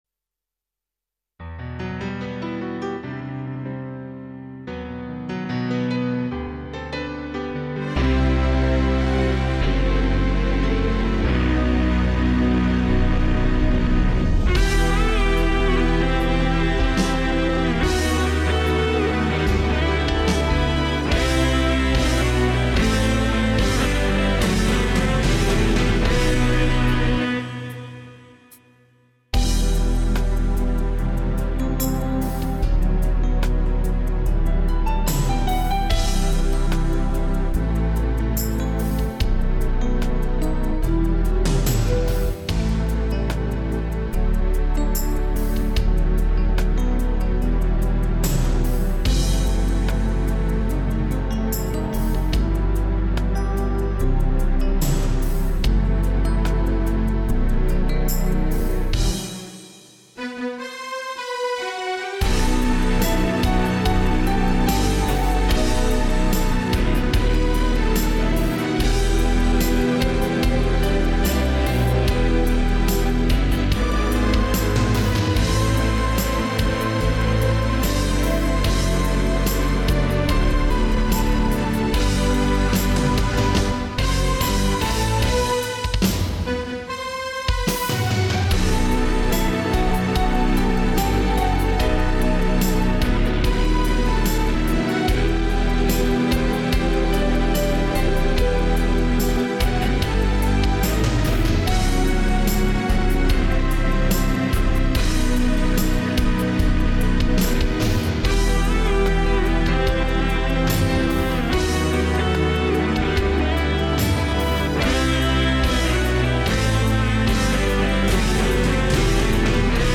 минусовка версия 32562